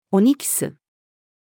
オニキス-female.mp3